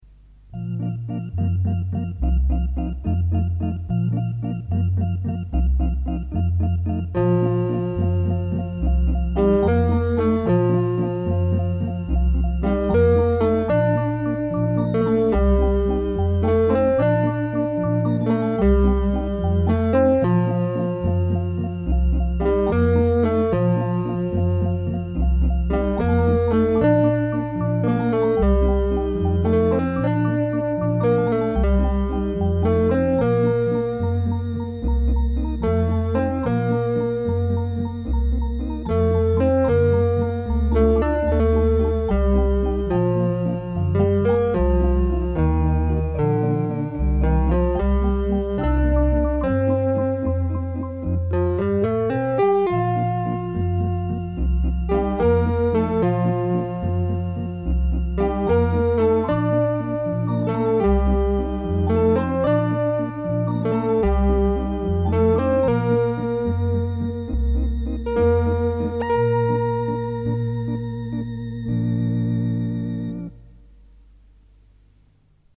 This sound, created by multi-track tape sync, is probably our only surviving record of it – the photo is from the Roland Synth Chronicle website which no longer provides this information, but we found the Roland Indonesian site with the photo and text “1975: SH-5 Roland’s first 2VCO analog synthesizer.
Here is the music and video extract, without the water and waves sound effects of the original film:
t200synth1.mp3